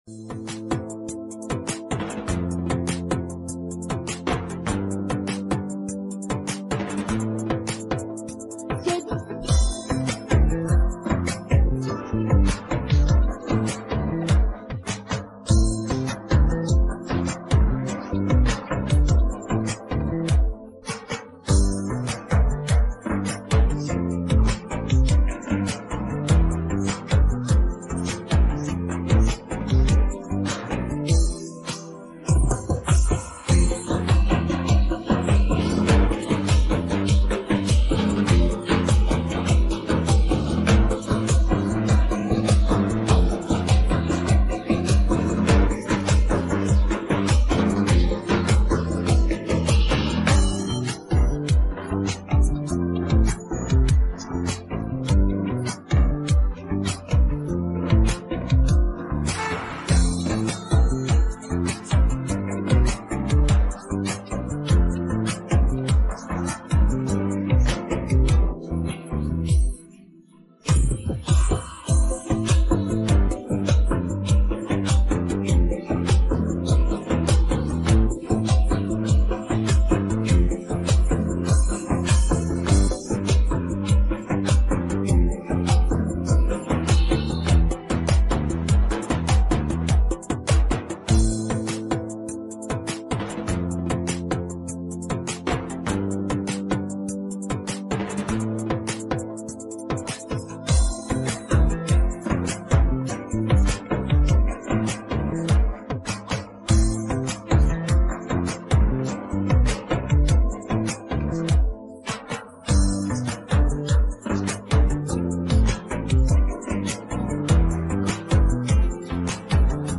تکخوان